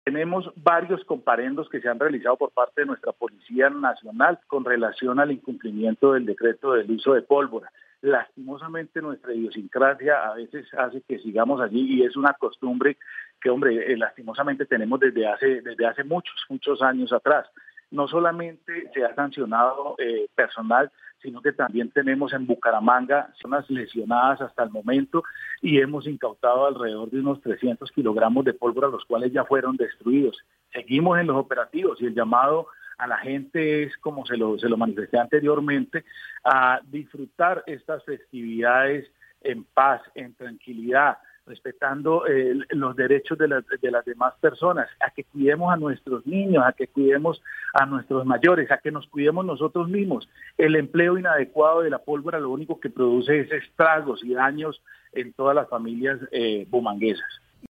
VOZ SECRETARIO DEL INTERIOR BUCARAMANGA